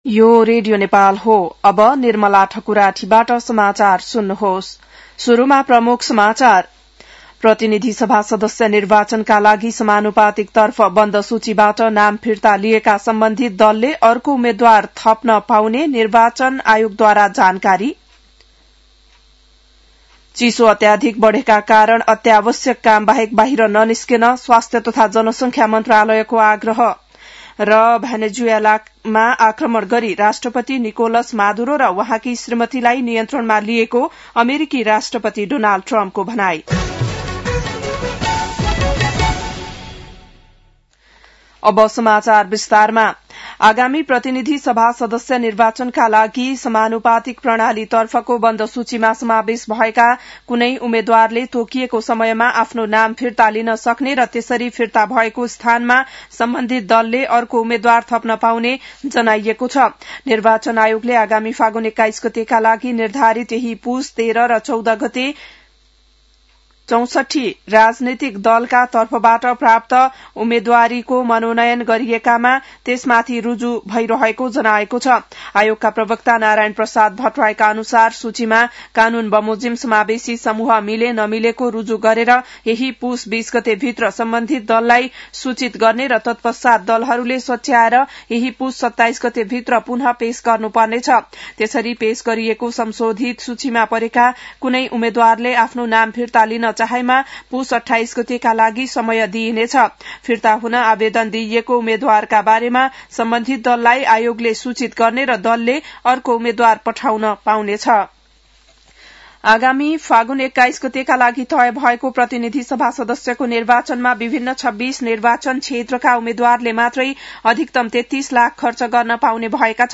बिहान ९ बजेको नेपाली समाचार : २० पुष , २०८२